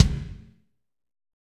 KIK F R K0UL.wav